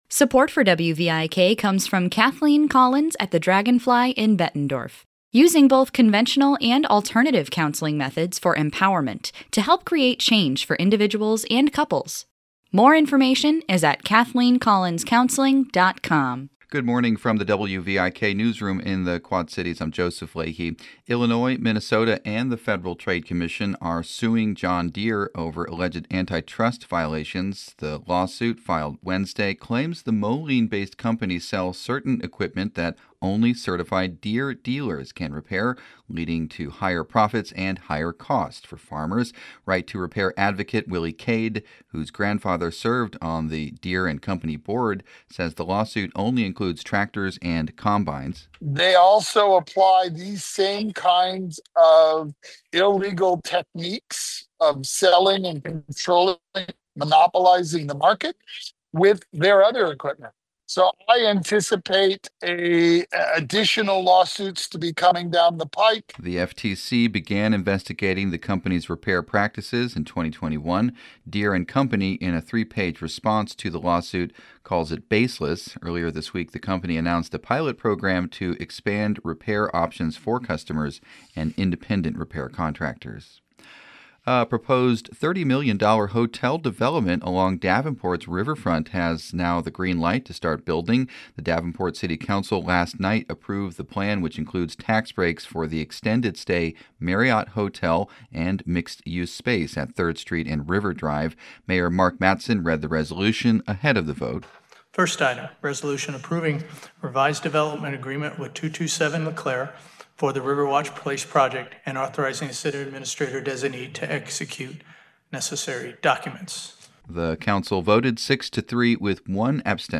Morning headlines from WVIK News.